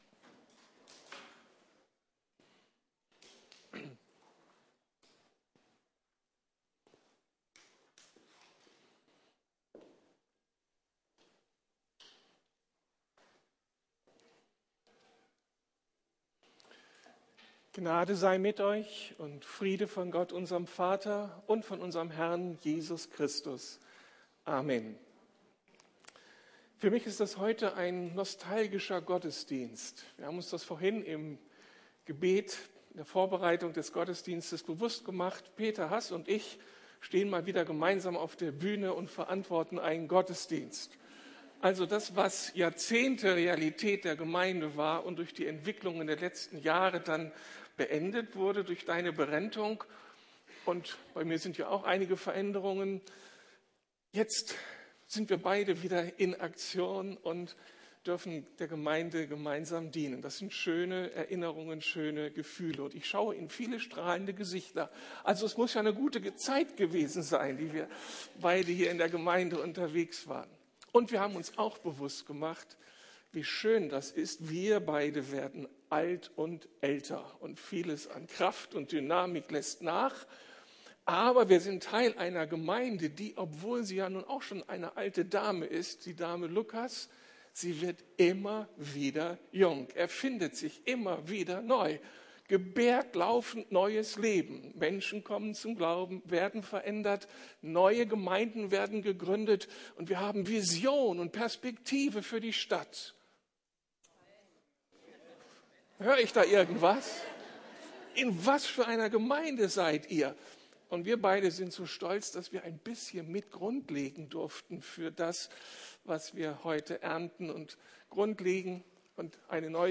Wenn der Himmel auf die Erde fällt ~ Predigten der LUKAS GEMEINDE Podcast